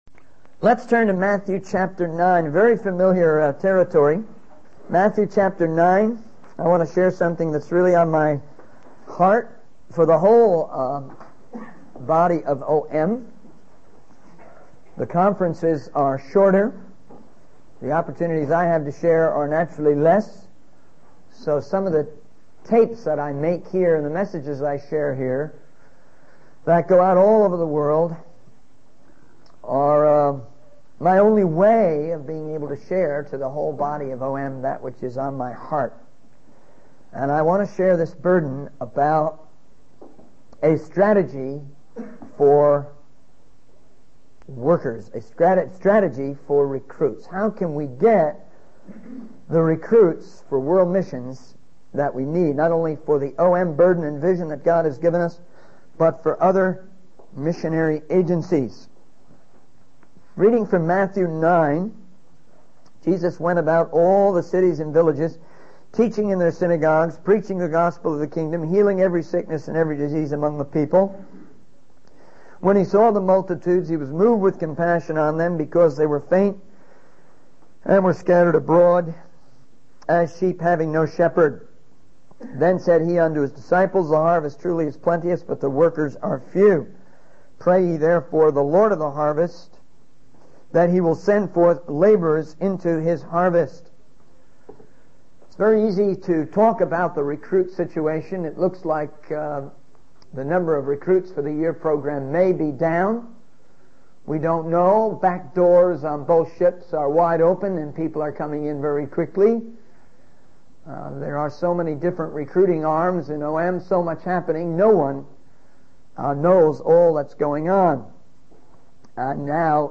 In this sermon, the speaker emphasizes the importance of using time wisely and not allowing television to consume too much of it.